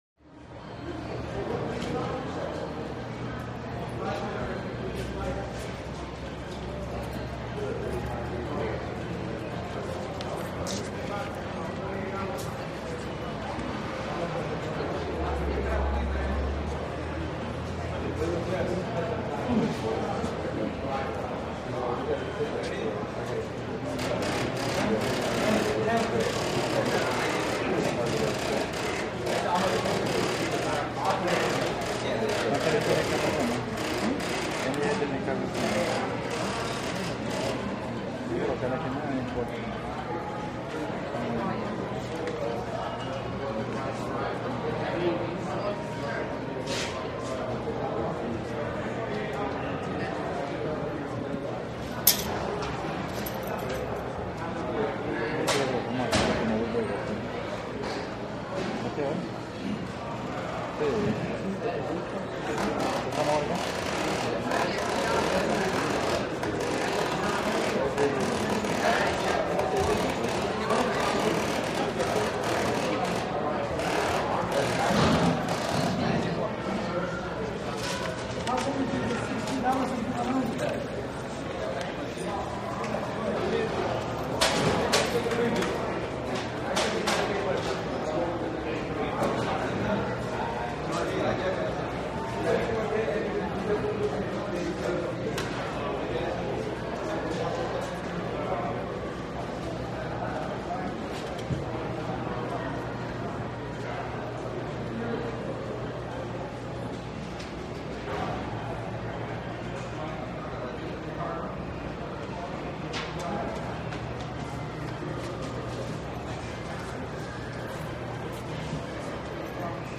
Large Office Or Government Building Interior. Close Computer Printer, Loud Reverberated Walla Medium Distant, Some Sparse Close Dialogue. Sounds Like Many People Waiting For Bureaucratic Service.